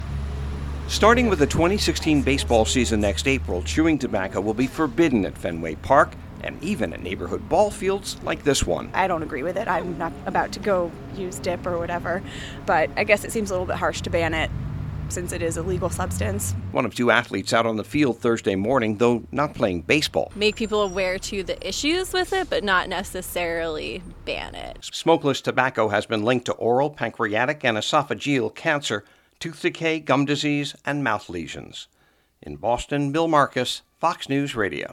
AN ULTIMATE FRISBEE PLAYER PRACTICING ON A BOSTON AREA BALL PARK THURSDAY MORNING SAYS SHE DISAGREES WITH A FULL BAN ON CHEWING TOBACCO OUTSIDE OF PROFESSIONAL STADIUMS.